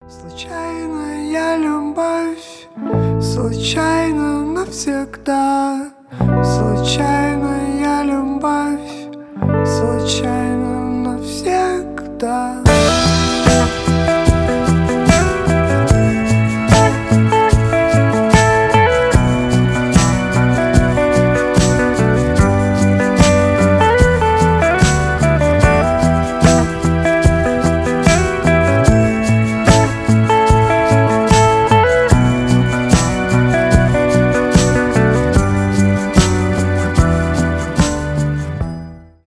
альтернатива , рок
indie rock